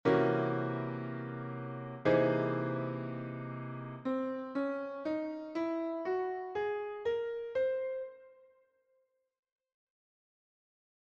A noter que les accords US_bV et US_bVI utilisent les notes de la gamme altérée et peuvent alors s’échanger facilement :